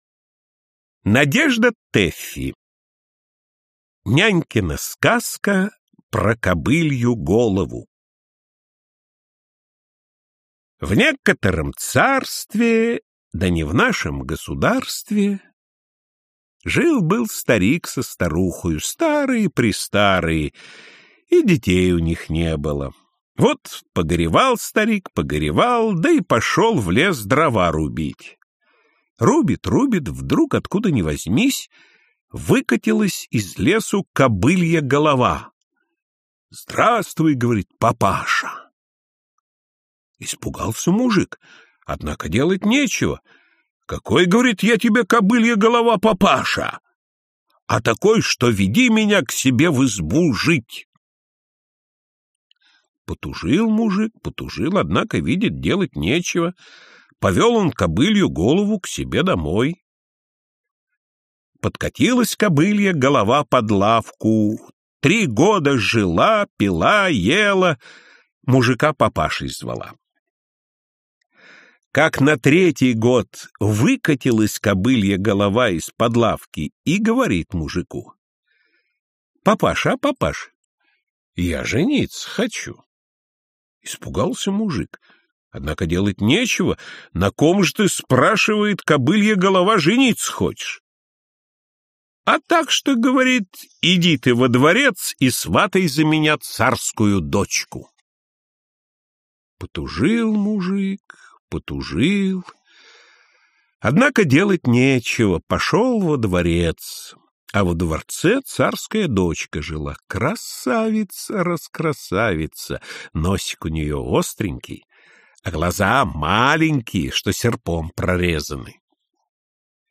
Аудиокнига Когда рак свистнул (юмористические рассказы) | Библиотека аудиокниг